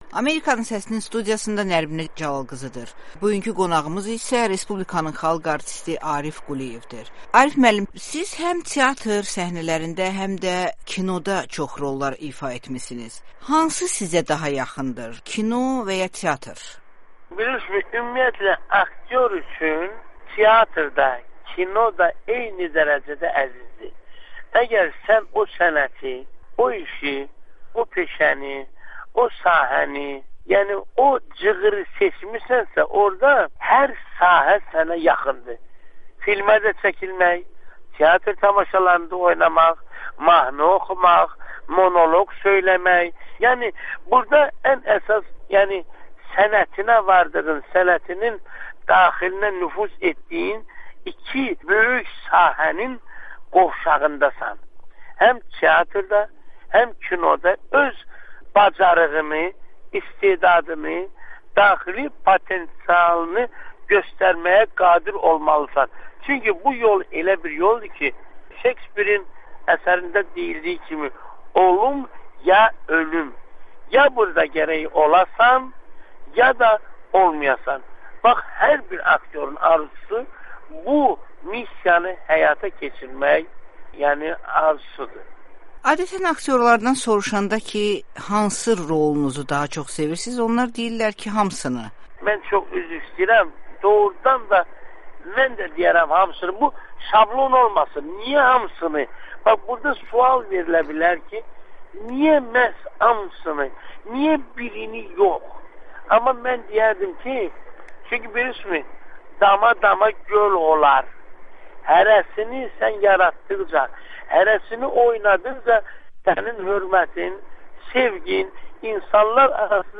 Respublikanın xalq artisti Arif Quliyevlə müsahibə